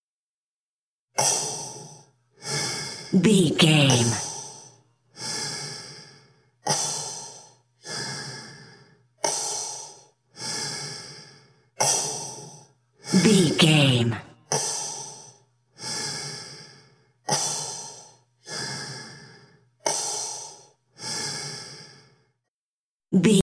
Hospital respiratory
Sound Effects
chaotic
anxious
emergency